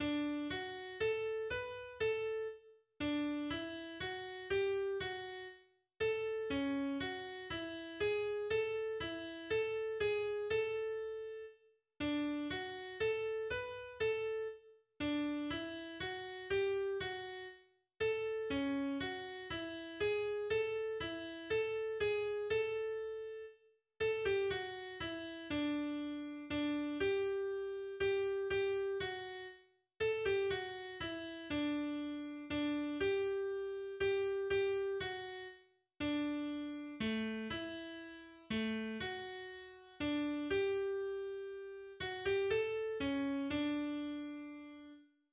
katholisches Kirchenlied des 19. Jahrhunderts
Die fröhliche und festliche Melodie, die – so Mang (Liederquell, 2015, S. 1107) - »Weihnachtsfreude ausstrahlt«, war schon früher dem weihnachtlichen Text »Still geschwinde, still ihr Winde, stört dem Kind nicht seine Ruh« von Heinrich Lindenberg (1712-1750) unterlegt und 1741 in dem »Kirchen- und Hausgesangbuch« Heinrich Lindenborns in Köln erschienen.